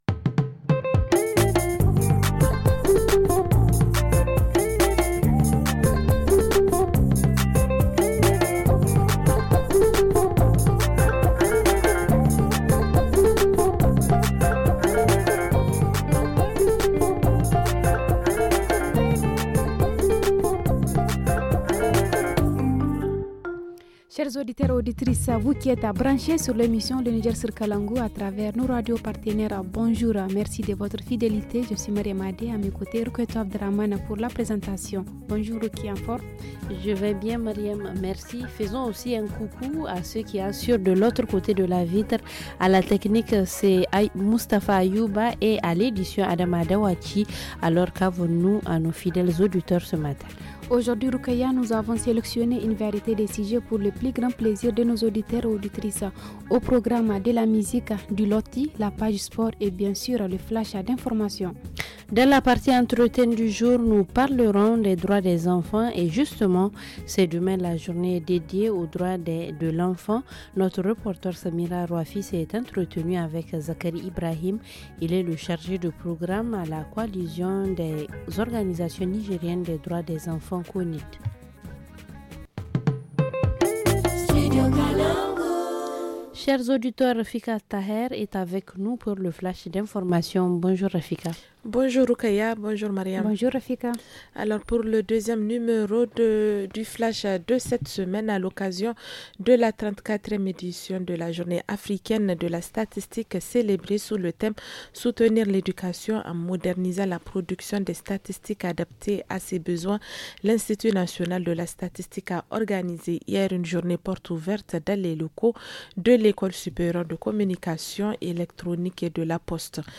La rubrique hebdomadaire traitera des mises en œuvre des centres de santé intégrés dans les 5 arrondissements communaux de Niamey. En reportage régional, nous allons mettre l’accent sur les activités du chef de canton de Bandé, dans la région de Zinder.